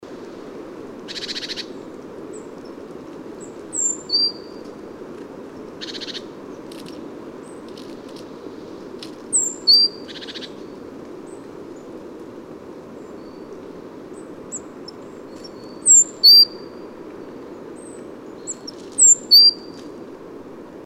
PFR07521, 130203, Great Tit Parus major, 3, excitement calls, European Blue Tit Cyanistes caeruleus, excitement calls, excitement calls (urgent),
Goldcrest Regulus regulus, excitement calls (urgent)